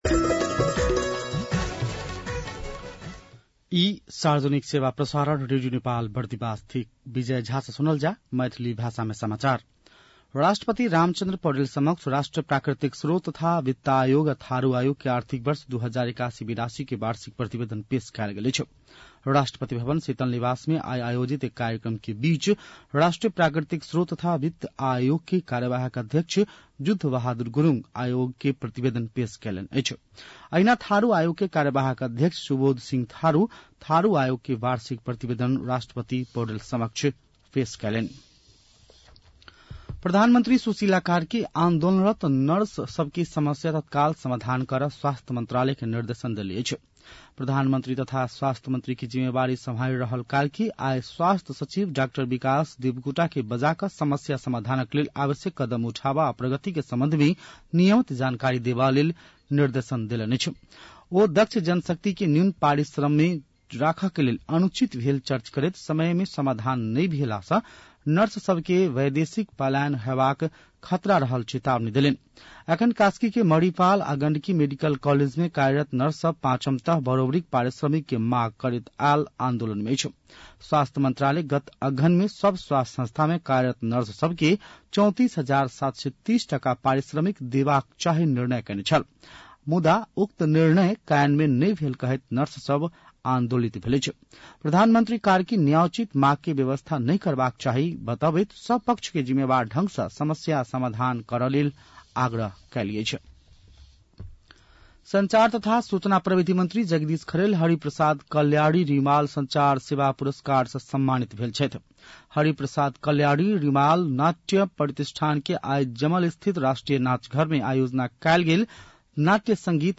मैथिली भाषामा समाचार : ३० असोज , २०८२
6.-pm-maithali-news-1-3.mp3